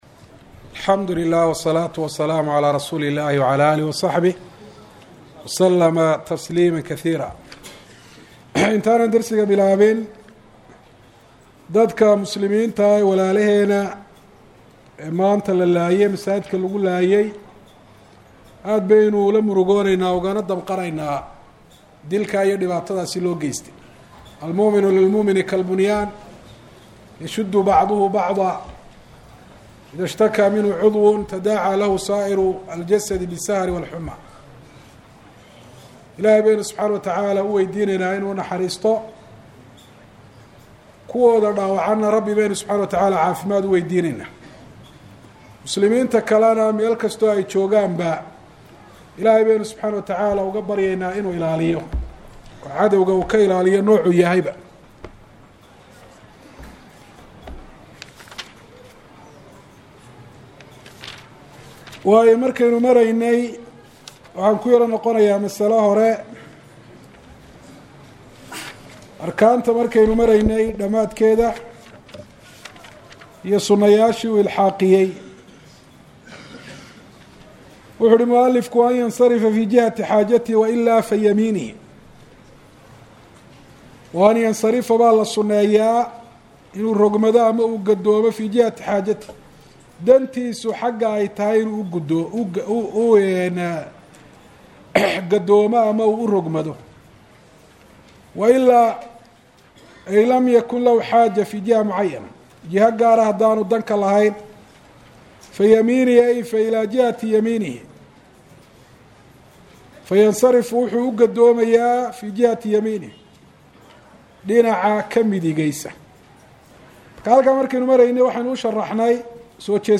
Masjid Nakhiil – Hargaisa